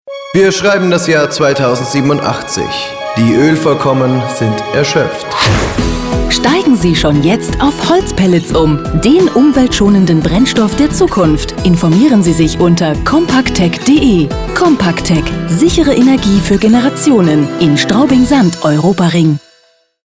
Sprecherin deutsch. Stimmalter Mitte 30. Sprecherin fĂŒr Werbung / OFF / Industriefilm / HĂ¶rspiel / Podcast
Sprechprobe: Werbung (Muttersprache):
female german voice over. Voice over artist for commercials, tv, radio, synchron, audio-books, documentaries, e-learning, podcast